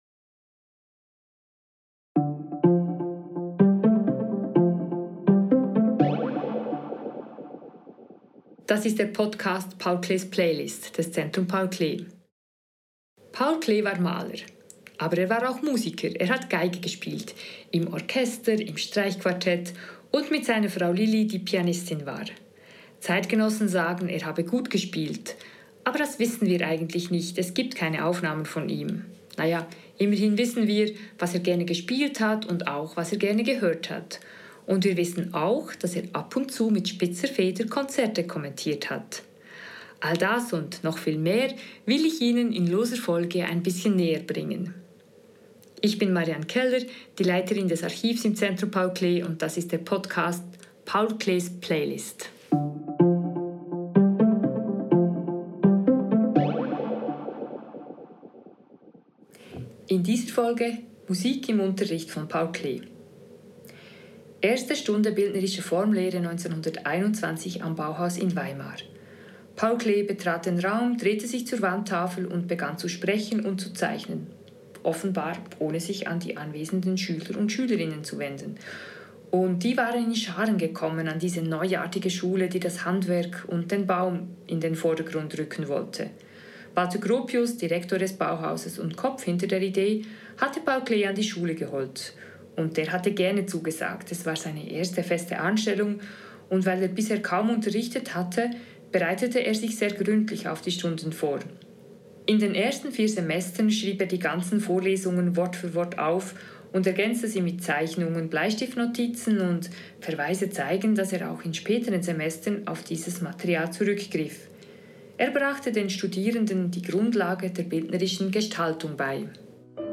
Gespielte Werke (Ausschnitte): Johann Sebastian Bach, Suite Nr. 3 D-Dur BWV 1068 Johann Sebastian Bach, Sonate Nr. 6 G-Dur BWV 1019c Johann Sebastian Bach, Fuge aus «Toccata und Fuge» d-Moll BWV 565 Johann Sebastian Bach, Wohltemperiertes Klavier, Fuge c-Moll BWV 847 Sprecherin